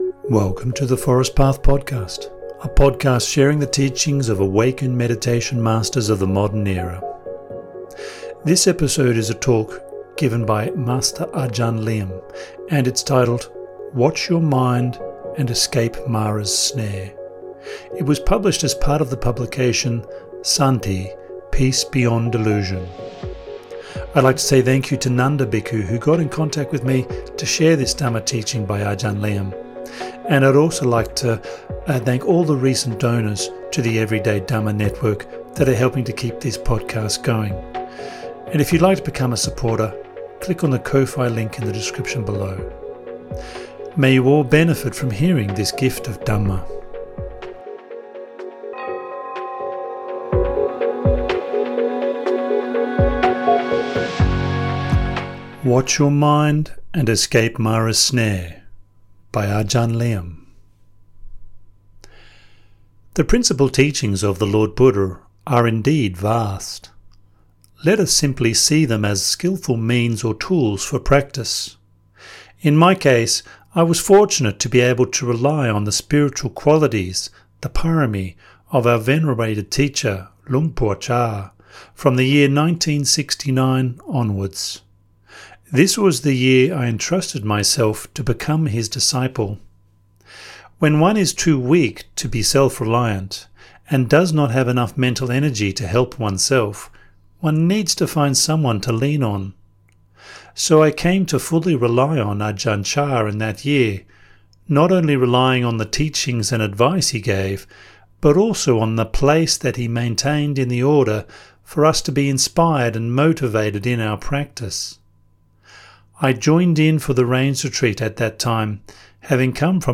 This episode is a talk